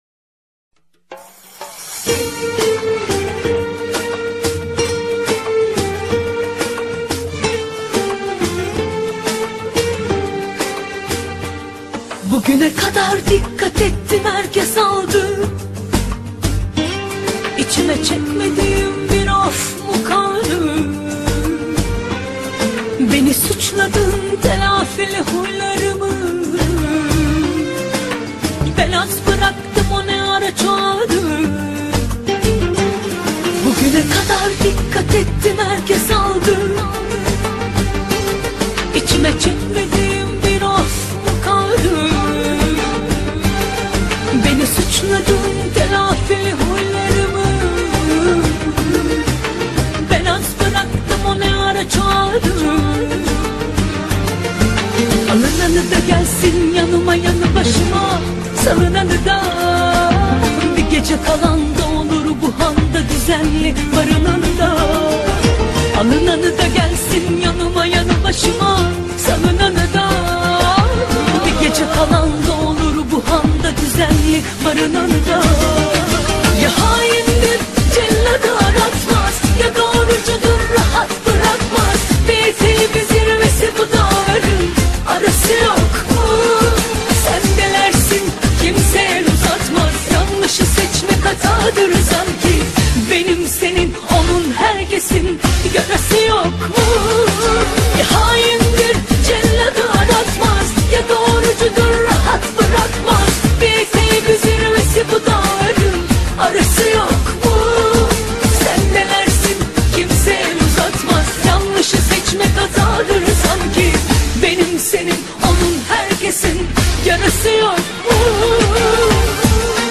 خواننده زن